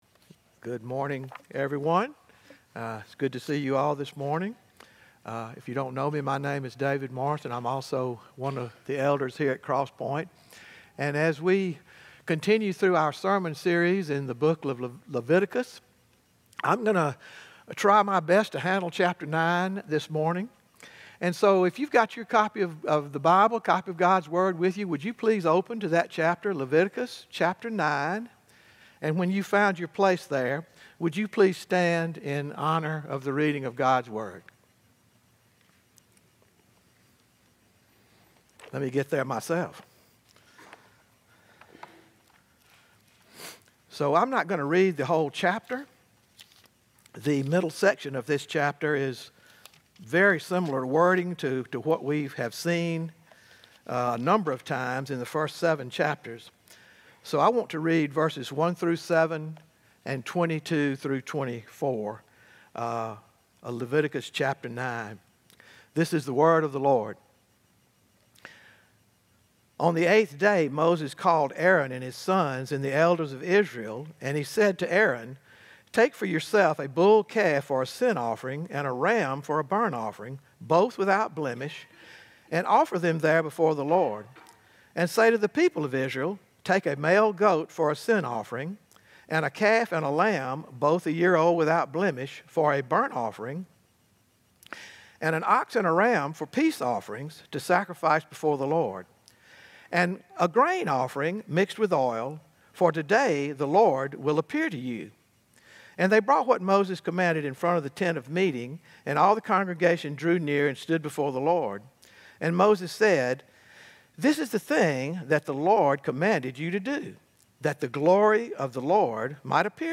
Bulletin and Sermon Notes 10-12-25